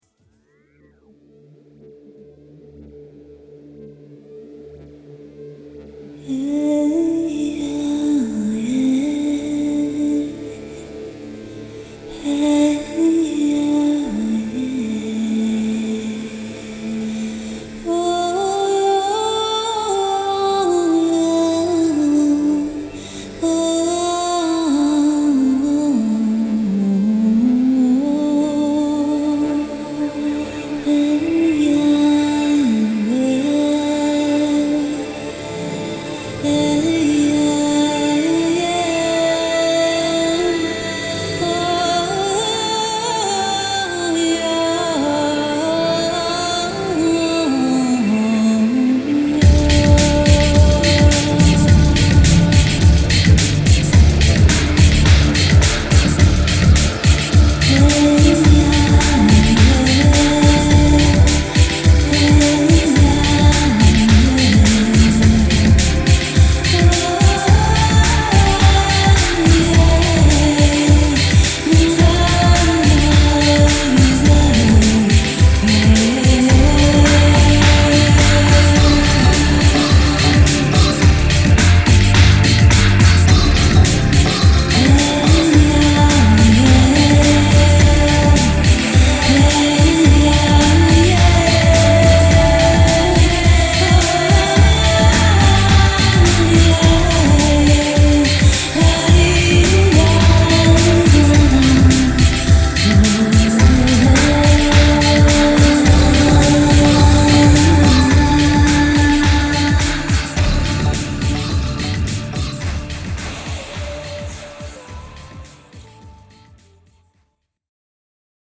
Performed Bass on